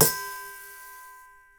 REDD PERC (23).wav